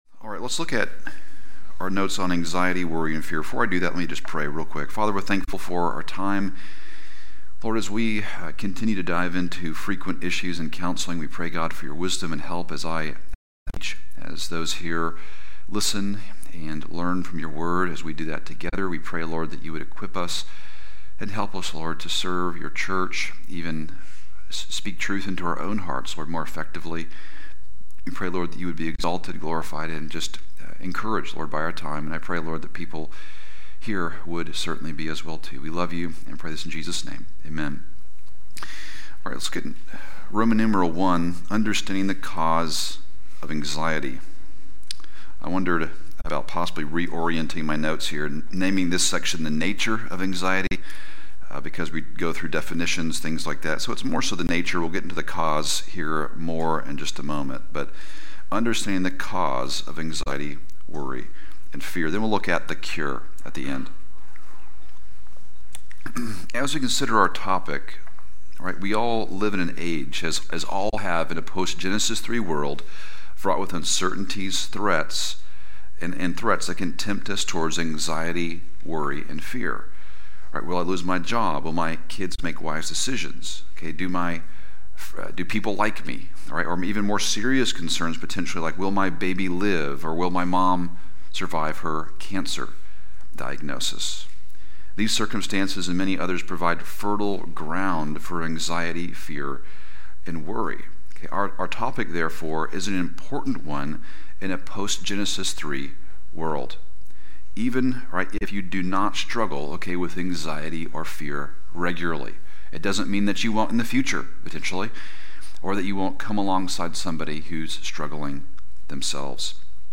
Adults / Elective Classes